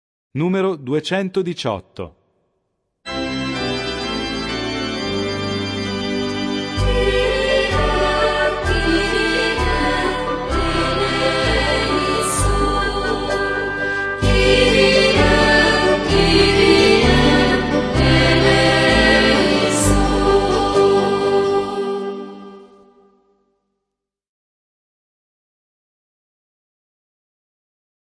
In questo tempo di emergenza sanitaria vogliamo sfruttare la tecnologia e proporvi una PROVA DEI CANTI VIRTUALE utllizzando gli mp3 qui sotto riportati, ad uso dell'Assemblea.
Atto penitenziale